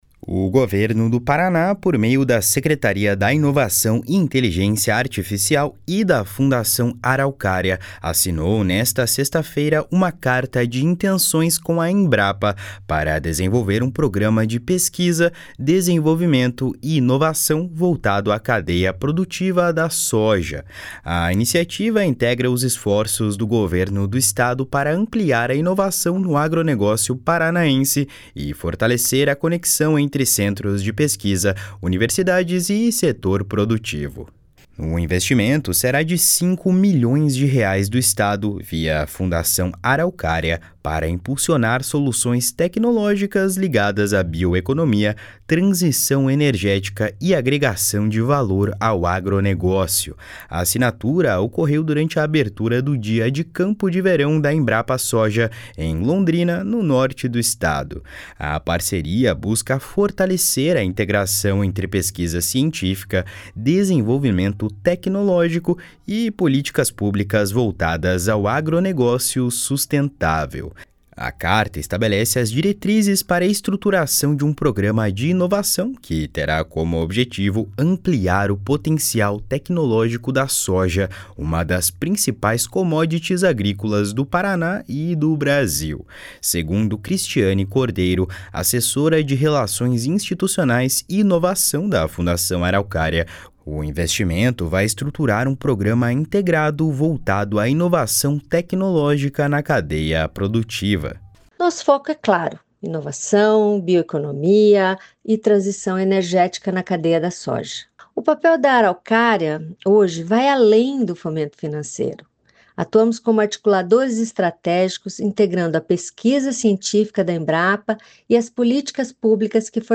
A assinatura ocorreu durante a abertura do Dia de Campo de Verão da Embrapa Soja, em Londrina, no Norte do Estado.